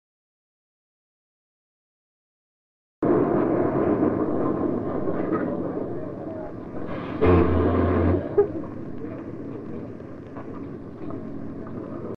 SS BAHAMA STAR Whistle (Short Sound Effects Free Download